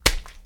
Plants Vs. Zombies Hit